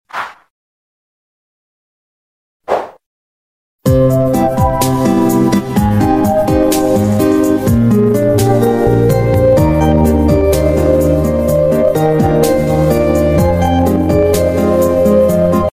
Sony PlayStation Error Animation Evolution